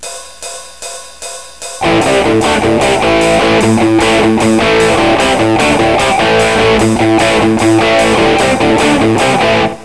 Обычно различия едва уловимы: один играет отдельные ноты, второй долбит по аккордам - но они определенно добавляют колориту.